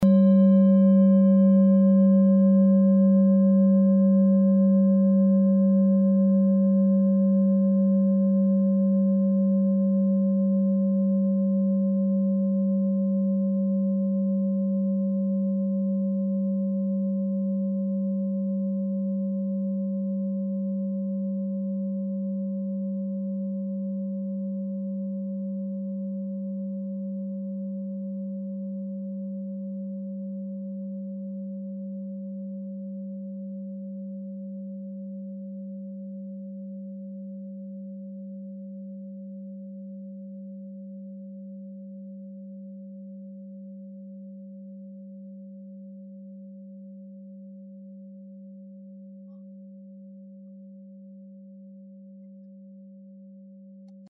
Klangschale TIBET Nr.12
Klangschale-Durchmesser: 19,7cm
Sie ist neu und ist gezielt nach altem 7-Metalle-Rezept in Handarbeit gezogen und gehämmert worden..
(Ermittelt mit dem Filzklöppel oder Gummikernschlegel)
Auf unseren Tonleiter entspricht er etwa dem "G".
klangschale-tibet-12.mp3